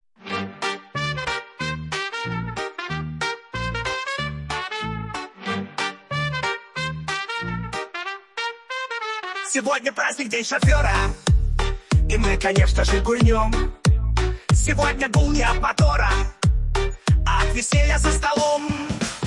Веселая застольная авторская песня
Застольная песня на день шофера в стиле «шансон»
Фрагмент примера исполнения: